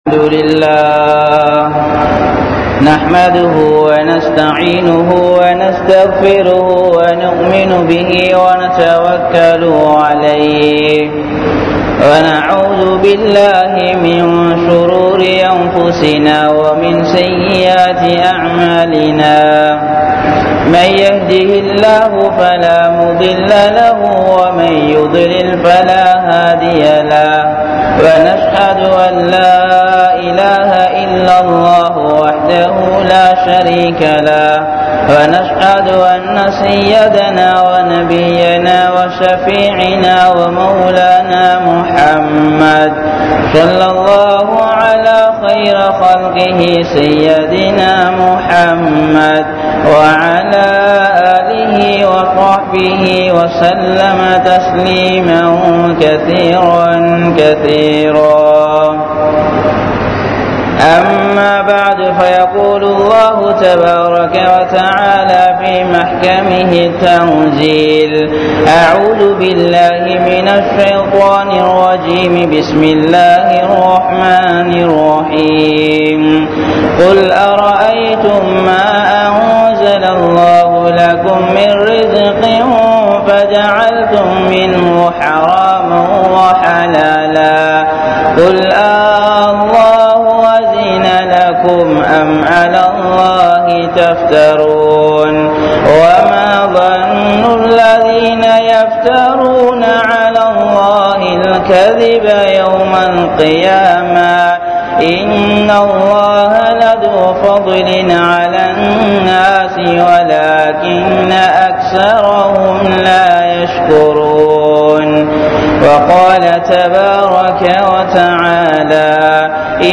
Halaal & Haraam (ஹலால் & ஹராம்) | Audio Bayans | All Ceylon Muslim Youth Community | Addalaichenai